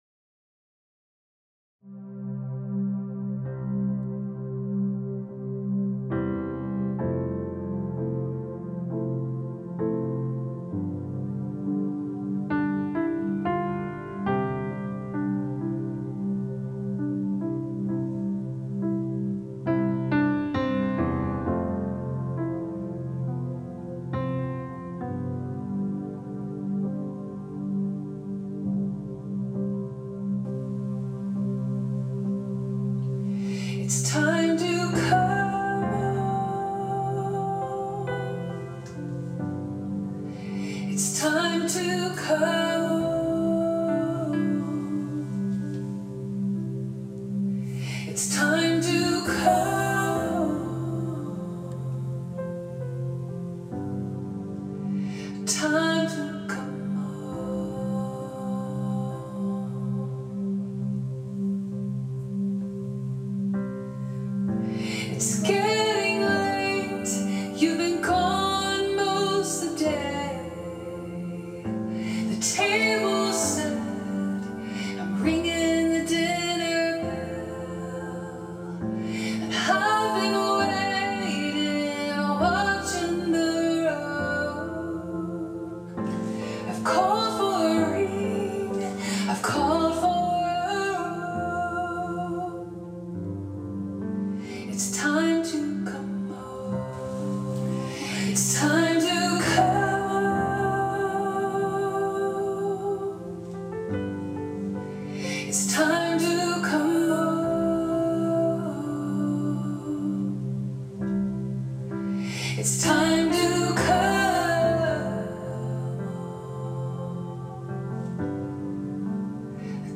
May 2025 Worship and Prayer.